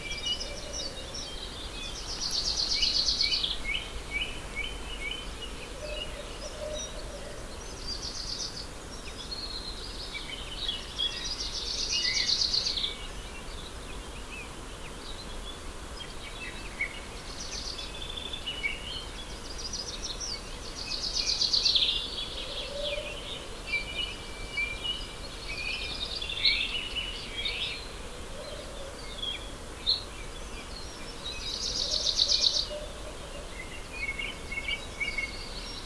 day_countryside.ogg